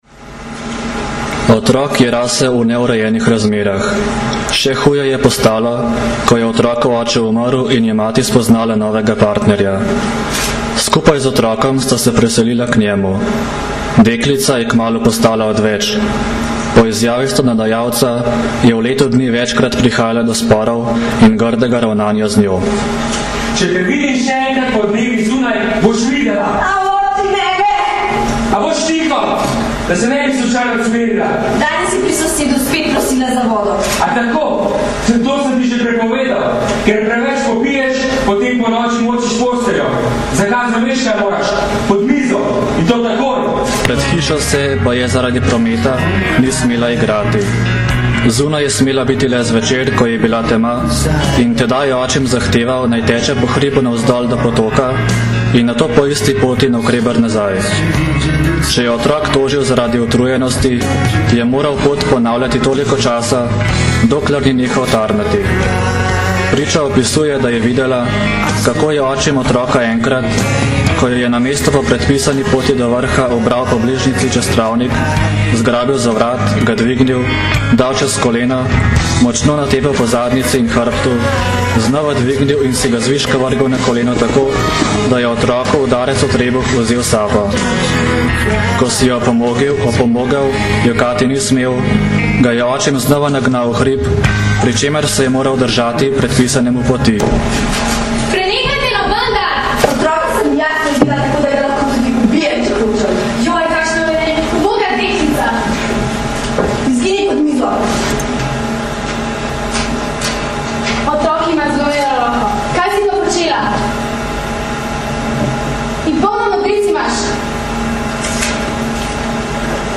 Danes, 9. aprila 2010, se je zaključil dvodnevni posvet z naslovom Nasilje nad otroki - razumeti in prepoznati, ki sta ga na Brdu pri Kranju organizirala Generalna policijska uprava in Društvo državnih tožilcev Slovenije.
Šlo je za izsek zgodbe iz resničnega življenja deklice, ki je po očetovi smrti postala odveč v skupnosti mamice in njenega novega partnerja. Predstavili so jo člani multimedijske sekcije Pirniče, ki deluje v okviru Kulturnoumetniškega društva Pirniče.